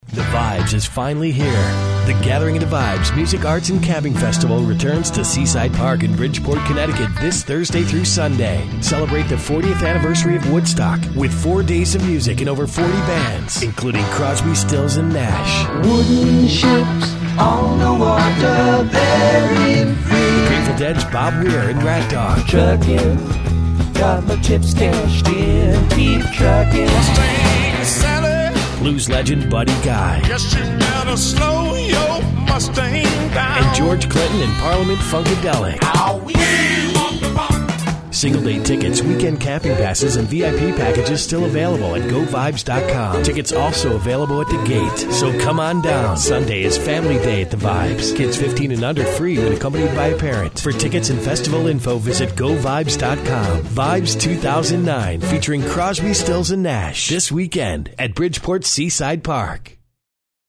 radio.mp3